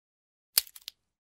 Звуки хруста веток
На этой странице собрана коллекция качественных звуков хруста и треска веток.